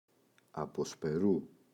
αποσπερού [apospe’ru] – ΔΠΗ